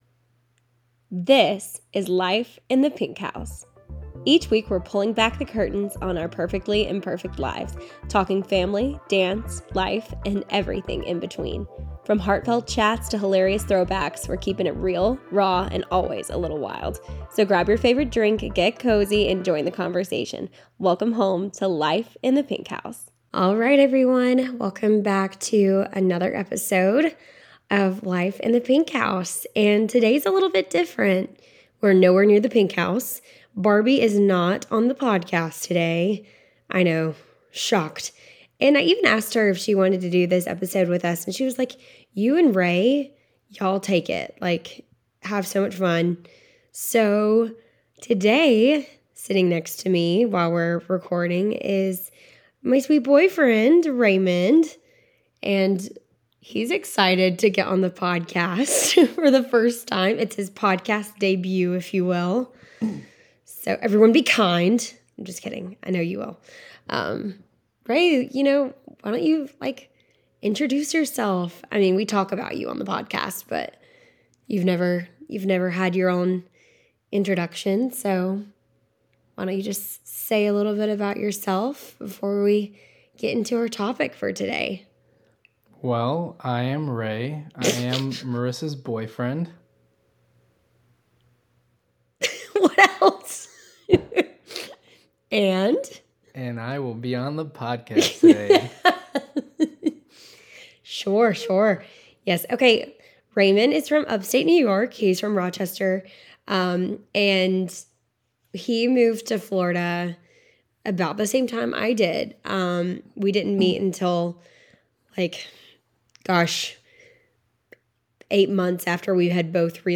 Stay tuned for frequent guests, brutally honest conversations, and lots of laughs.